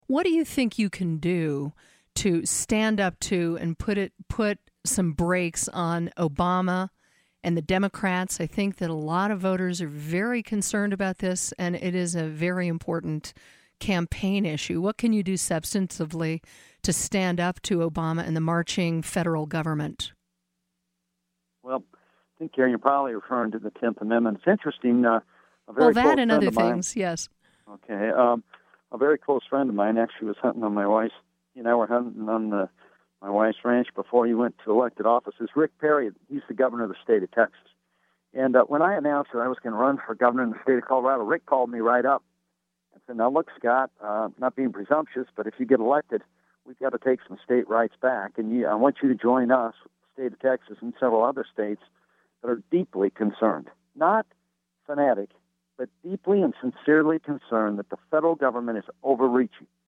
Gubernatorial candidate Scott McInnis, in the midst of a heated disagreement with the “Tea Party” groups who feel he has tried to hypocritically co-opt them, is trying really hard to prove out his conservative bonafides. Earlier this week, McInnis was interviewed for former Sen. John Andrews’ Backbone Radio program–apparently, and we confess to being entirely ignorant of this, McInnis is ‘close friends’ with Texas Gov. Rick Perry: